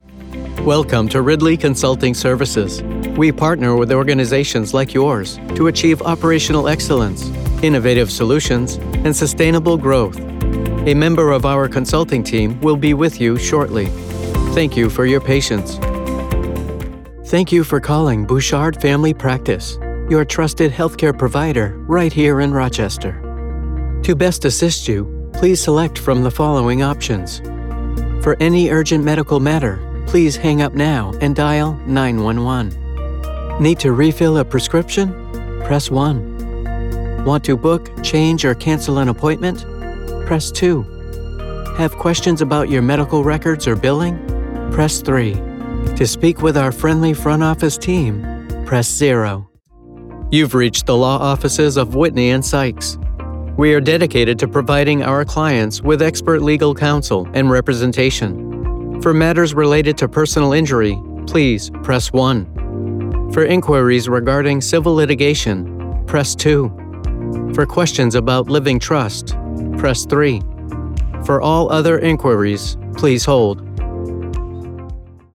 Male
I have a conversational tone that is natural, believable, and friendly.
Phone Greetings / On Hold
Various Telephony Samples
0812Telephony_Mixdown_1.mp3